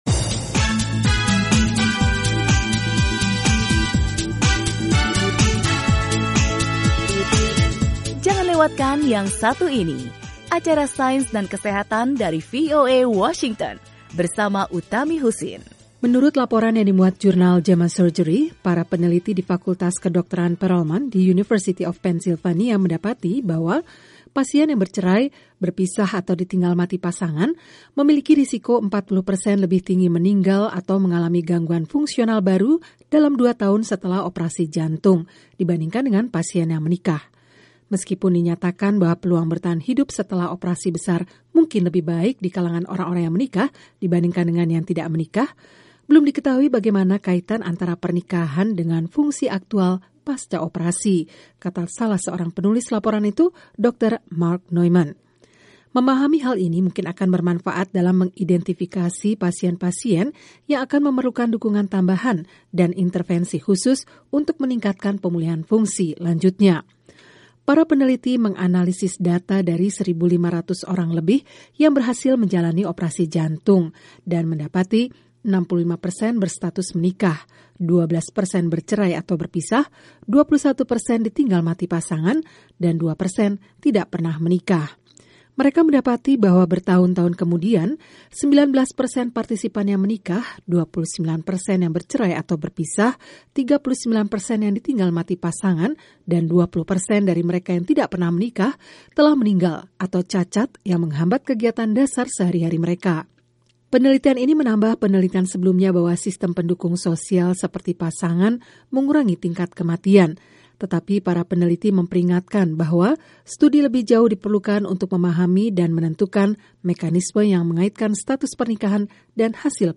Suatu penelitian baru-baru ini mendapati bahwa menikah dapat mengurangi kematian setelah operasi jantung. Laporan